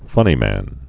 (fŭnē-măn)